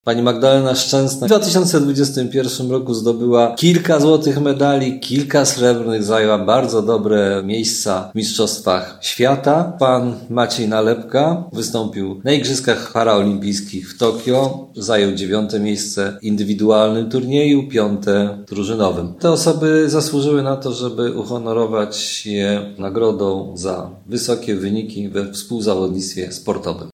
Mówi prezydent Tarnobrzega Dariusz Bożek.